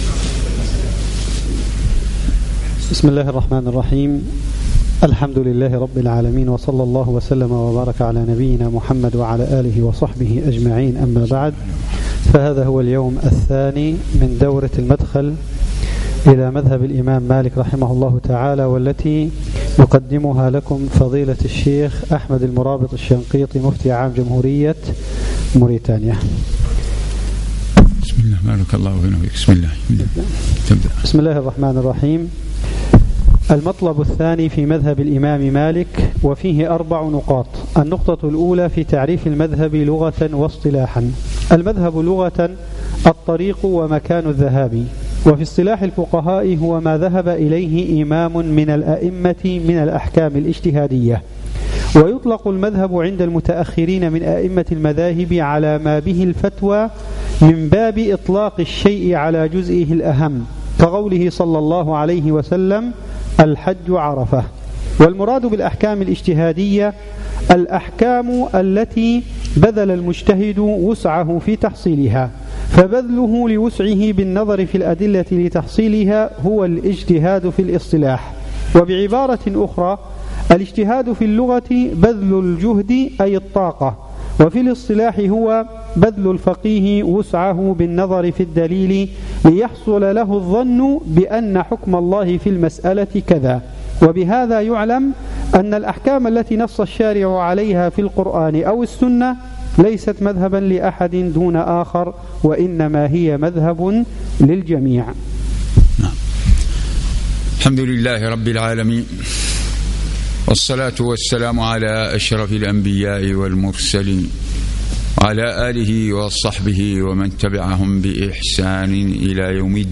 صباح الثلاثاء 21 جمادى الأولى 1437 الموافق 1 3 2016 بمبني تدريب الأئمة والمؤذنين
الدرس الثالث